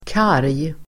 Ladda ner uttalet
Uttal: [kar:j]